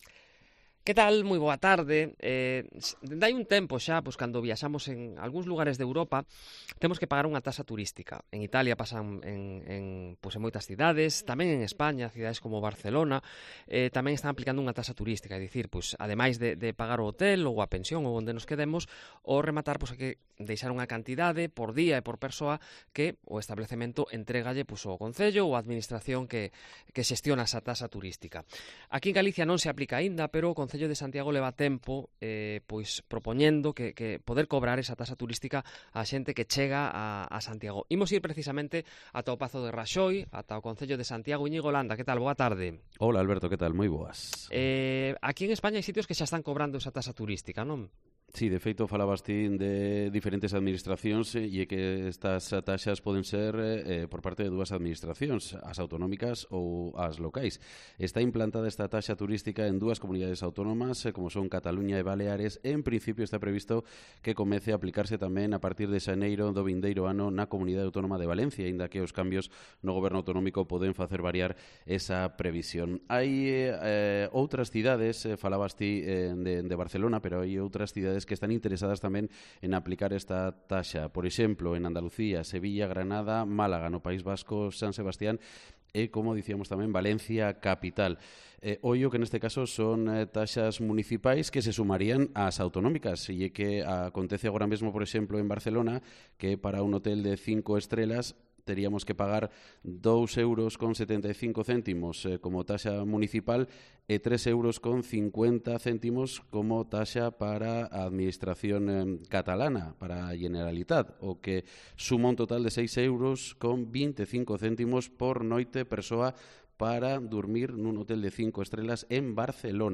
Entrevista en COPE Galicia sobre la tasa turística con la edil de Santiago, Miriam Louzao